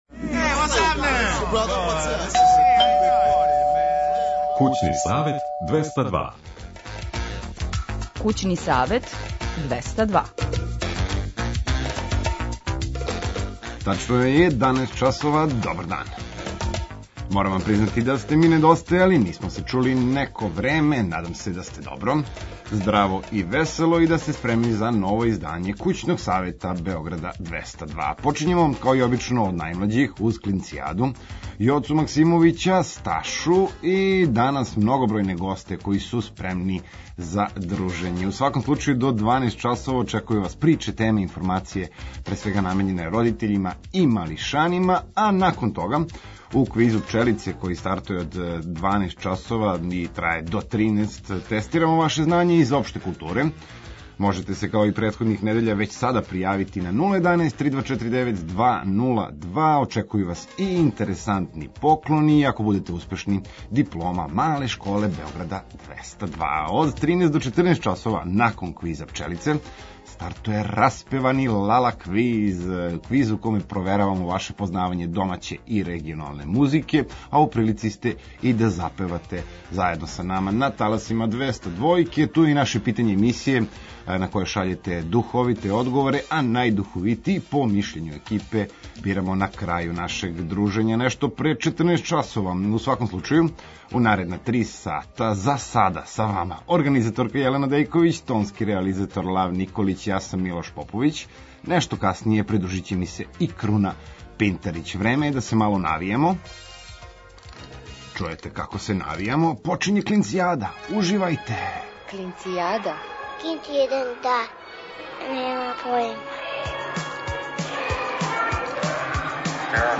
Од подне почиње и „Мала школа 202”, односно, квиз знања, а за њим и „Распевани квиз” у ком сваког учесника очекује поклон.